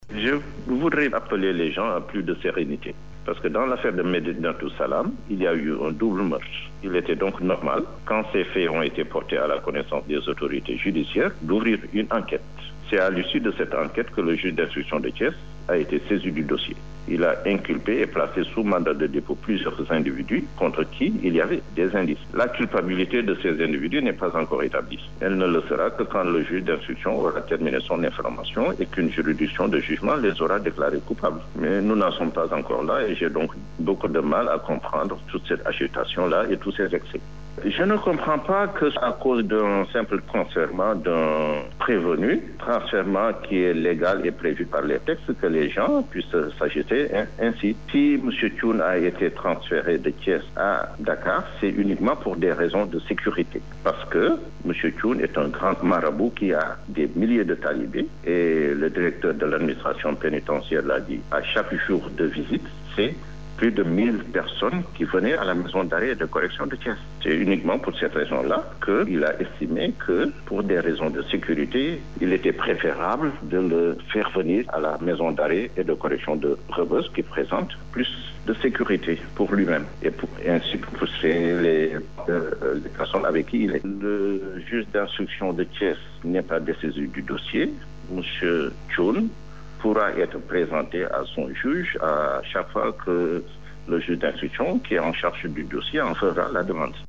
Pour le directeur des affaires criminelles et des grâces ce transfèrement de Cheikh Bethio Thioune est légal et prévu par les textes. Souleymane Kane soutient que c’est pour des raisons de sécurité que l’administration pénitentiaire a pris cette mesure. Il intervient sur les ondes de la Rfm.